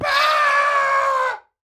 Minecraft Version Minecraft Version 1.21.5 Latest Release | Latest Snapshot 1.21.5 / assets / minecraft / sounds / mob / goat / screaming_death1.ogg Compare With Compare With Latest Release | Latest Snapshot
screaming_death1.ogg